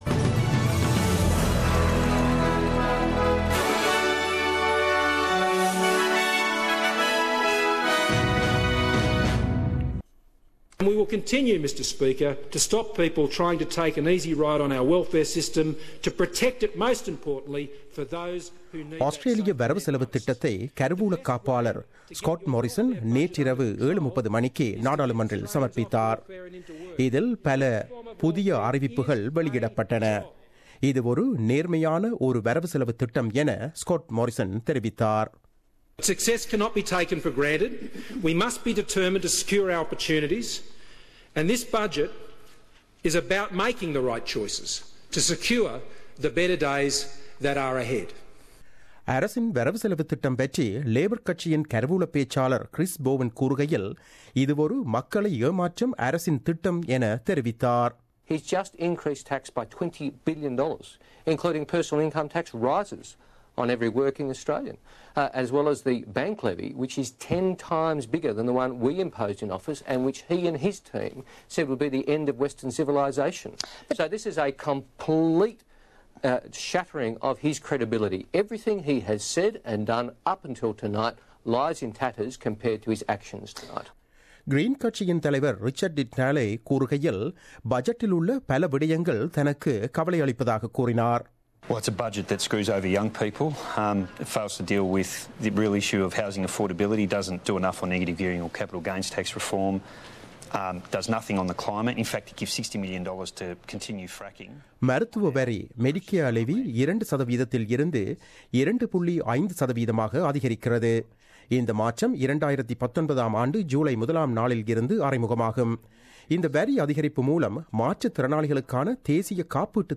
10/05/2017 Australian News